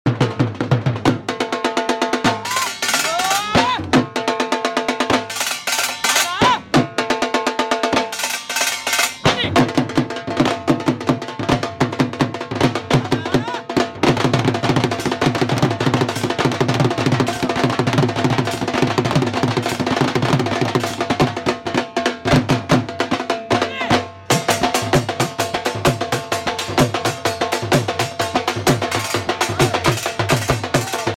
Dhol beat